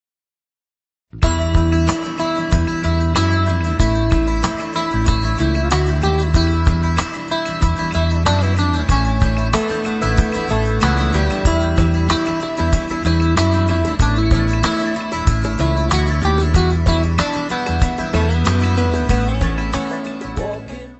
baixo, voz
guitarra, teclas, voz.
Music Category/Genre:  Pop / Rock